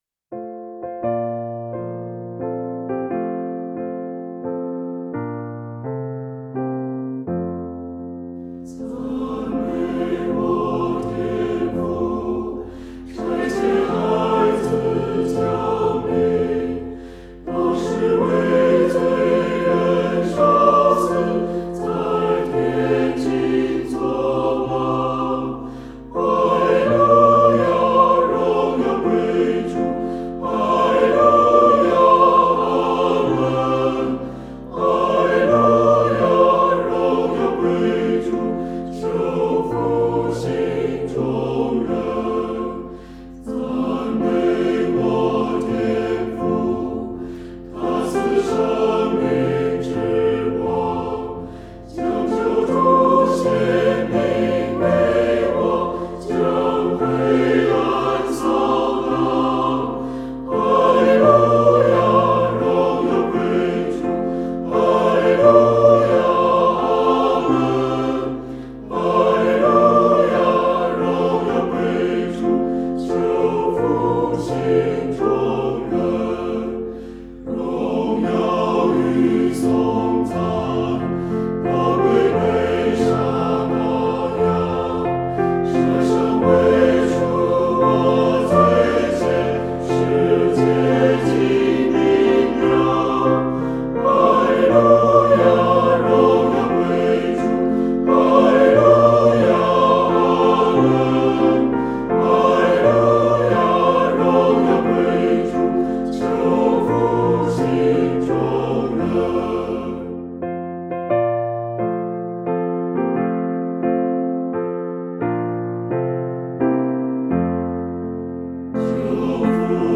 唱诗：求复兴众人（新259）